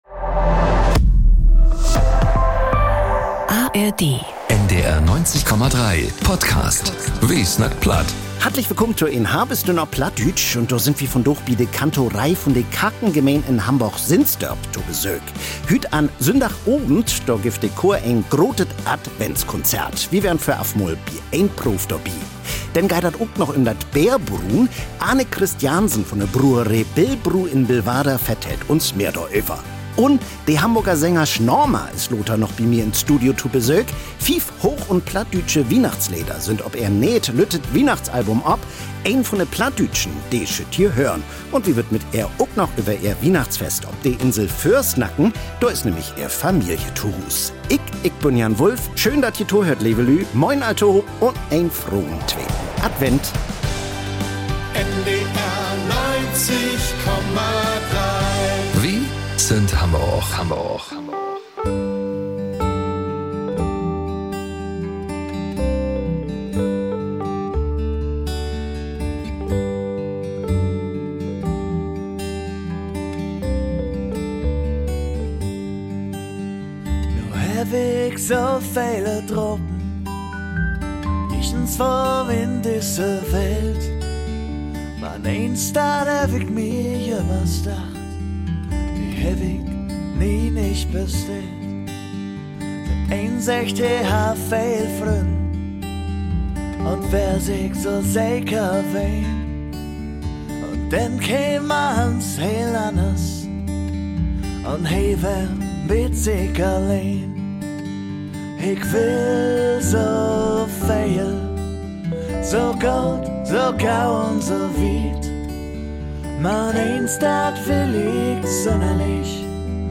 Wi weern voraf mal bi en Proov dorbi.